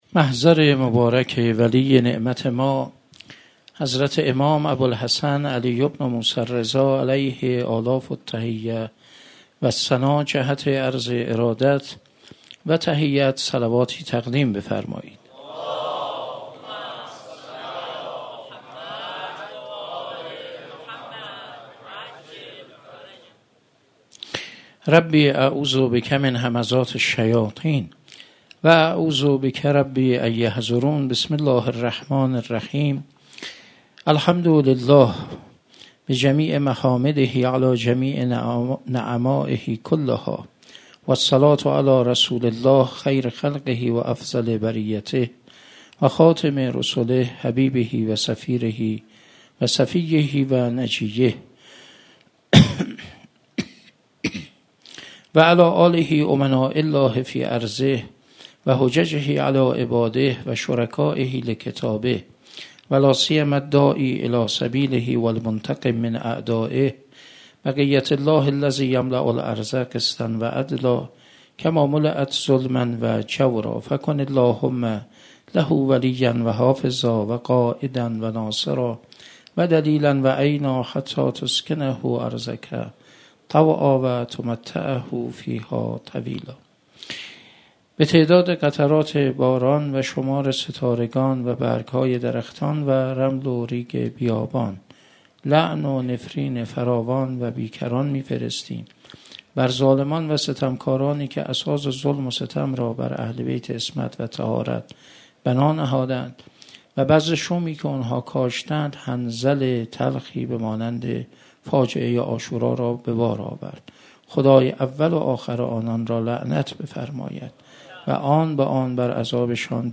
شب سوم محرم 97 - مصباح الهدی - سخنرانی